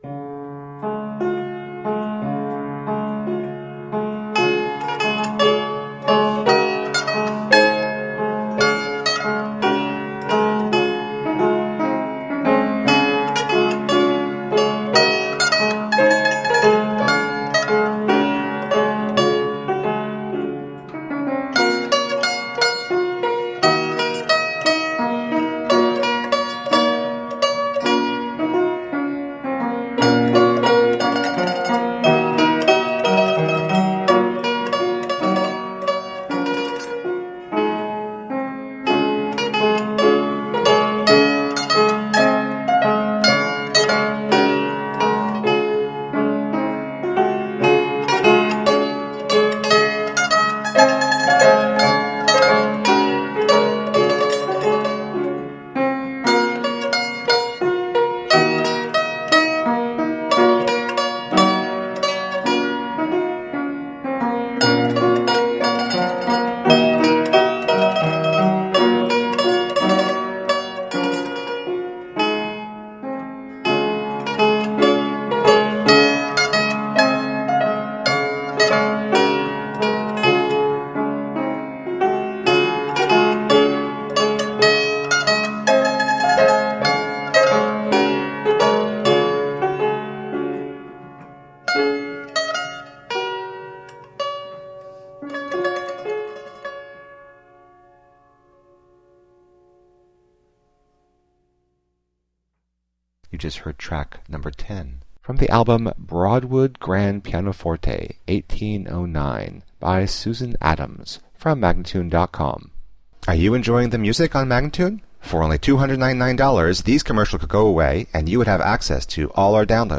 Tagged as: Classical, Instrumental Classical, Piano